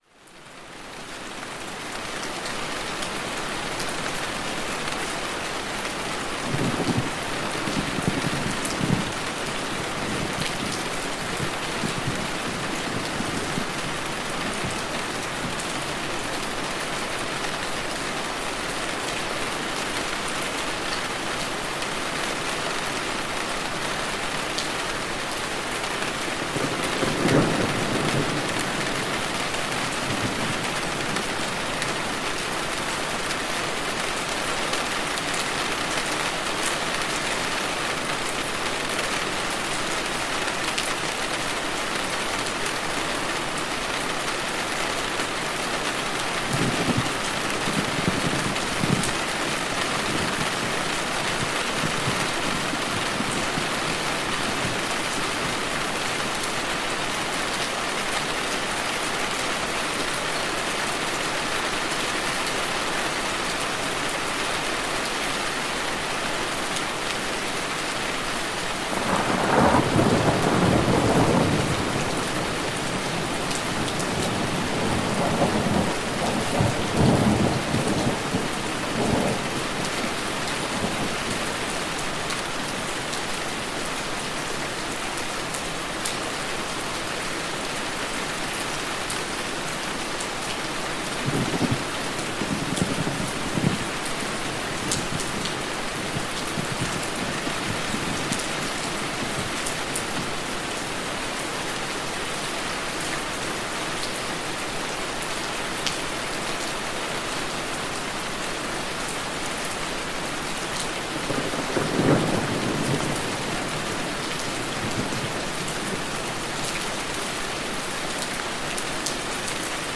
Deep Rain Relaxation—Soothing Rainfall for Easy Sleep